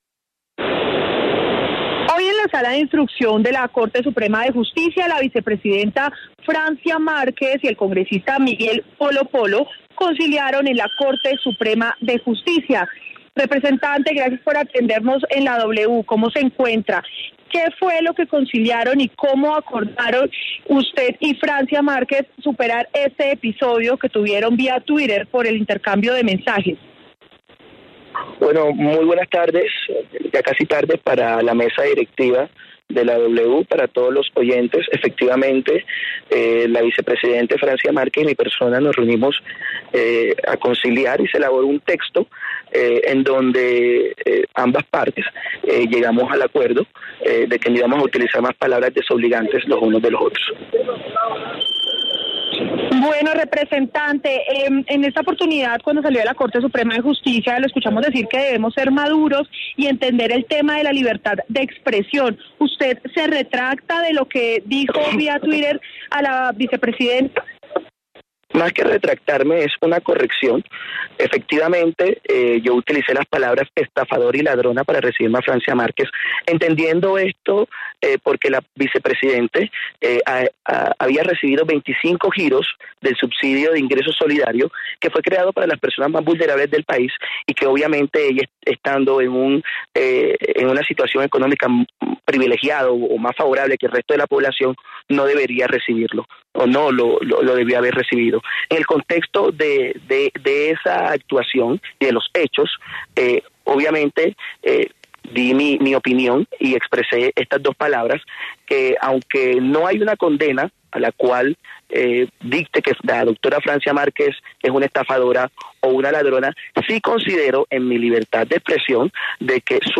“La vicepresidenta Francia Márquez y mi persona nos reunimos a conciliar. Se elaboró un texto en el que ambas partes llegamos al acuerdo de que no íbamos a utilizar más palabras desobligantes uno del otro”, relató Polo Polo en diálogo con La W.